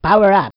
voice_power_up.wav